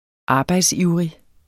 Udtale [ ˈɑːbɑjds- ]